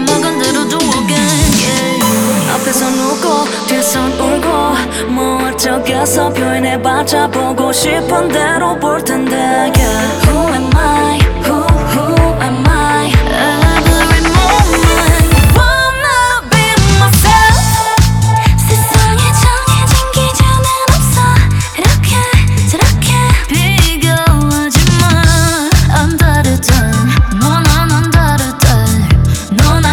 • K-Pop